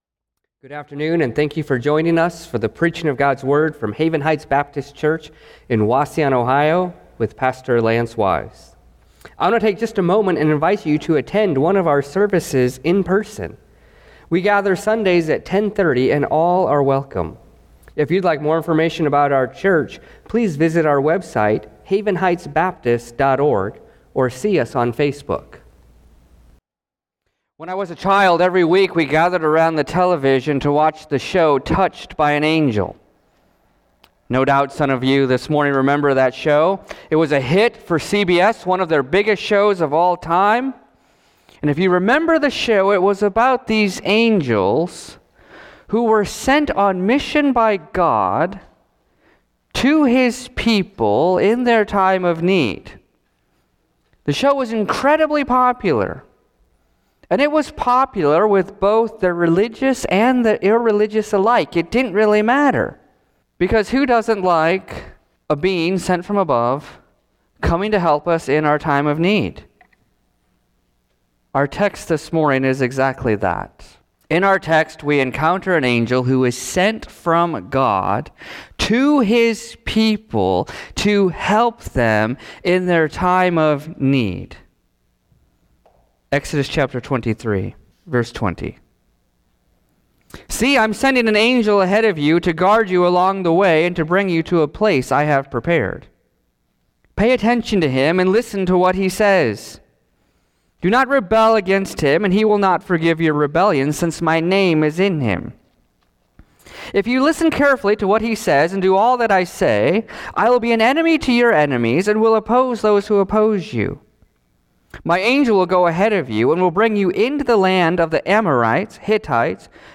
Sermons | Haven Heights Baptist Church